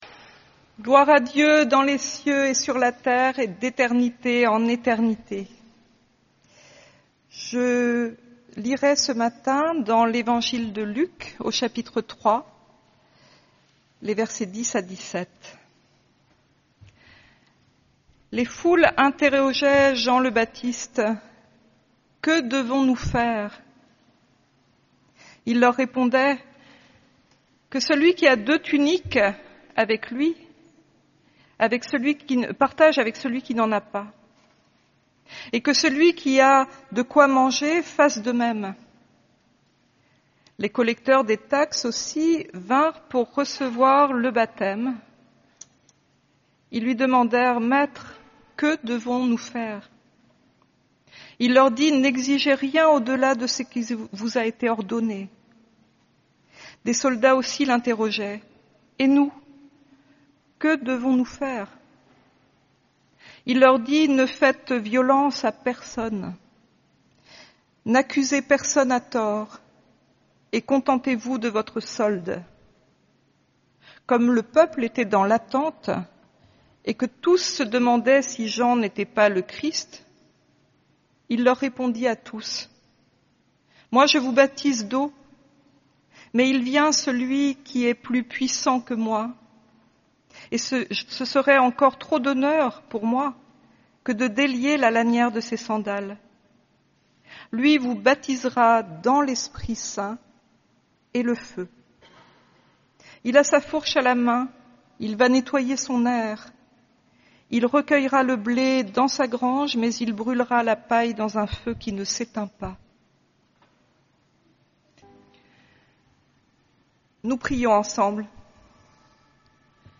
Culte du 6 juillet 2025.
La prédication du 6 juillet 2025